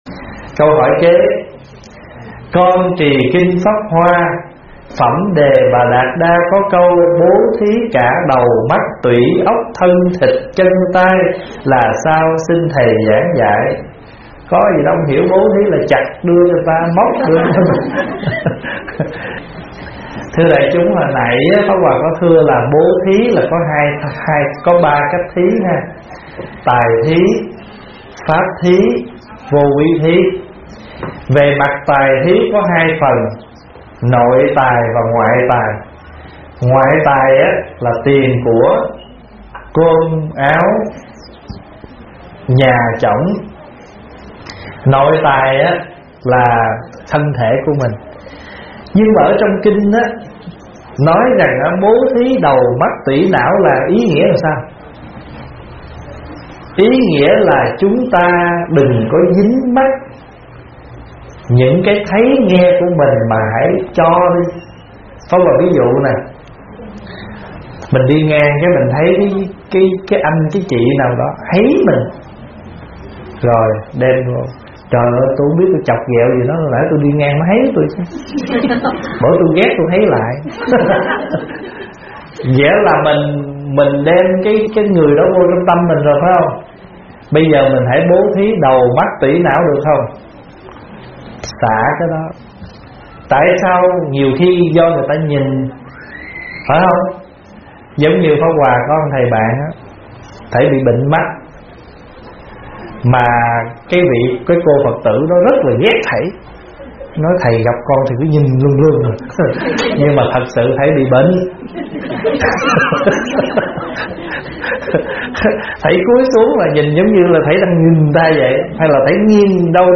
Nghe Mp3 thuyết pháp Bố Thí Óc, Tuỷ, Não - ĐĐ. Thích Pháp Hòa
Mời quý phật tử nghe mp3 vấn đáp Bố Thí Óc, Tuỷ, Não do ĐĐ. Thích Pháp Hòa giảng